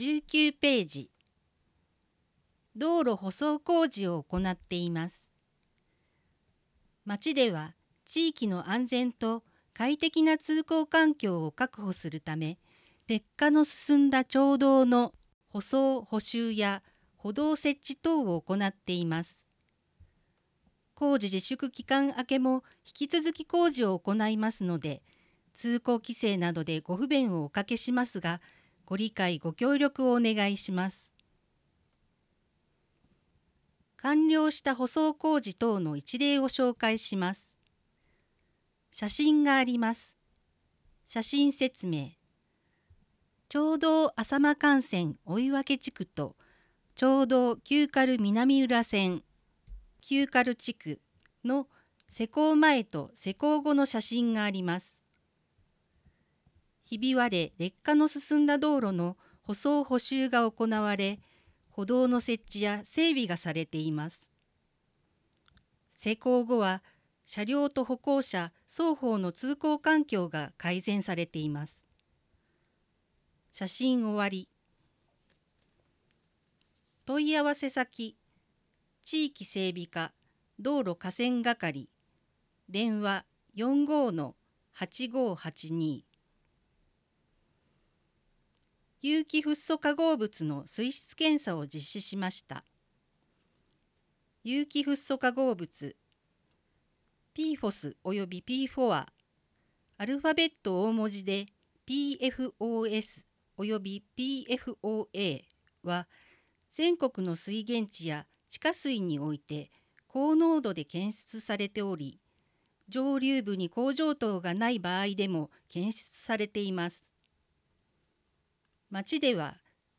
音声データ　軽井沢図書館朗読ボランティア「オオルリ」による朗読